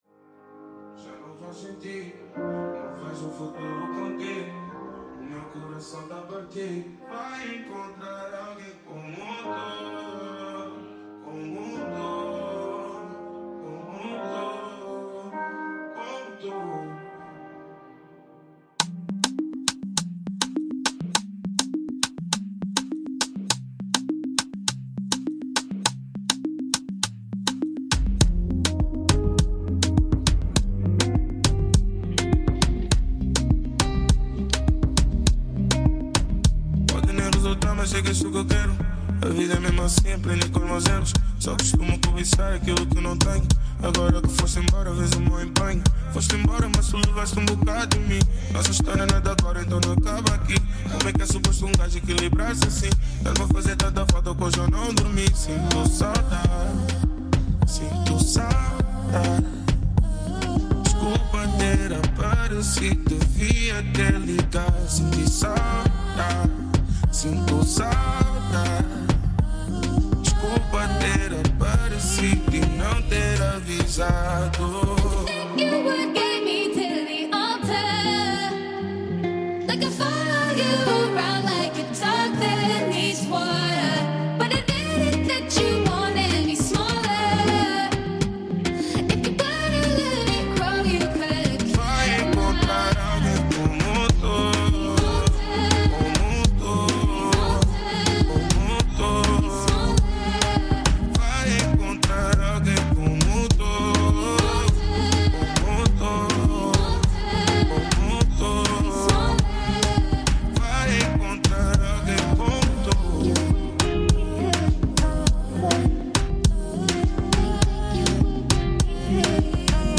Check out our latest musical selection, featuring a vibrant blend of contemporary pop and classic Portuguese rock. From exclusive mashups to rising talents in the indie scene, this program offers an eclectic, rhythmic sonic journey.